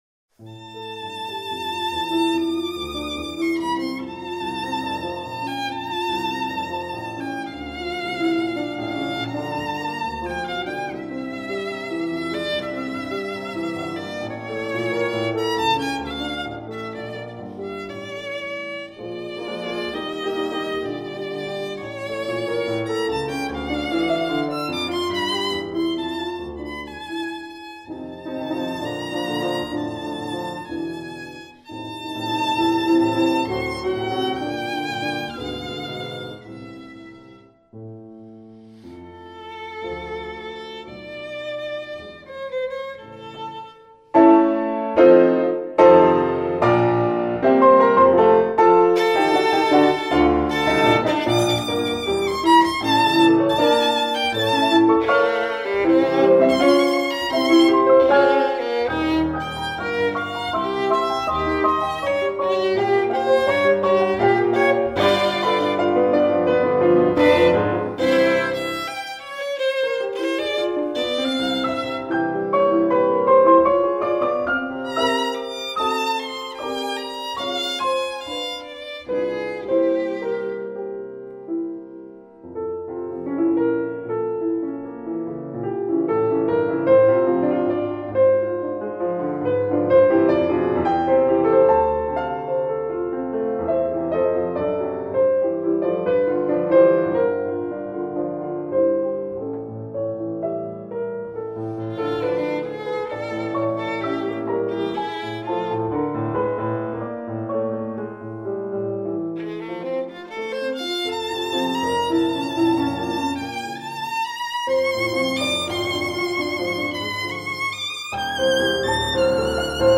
Un poco presto e con sentimento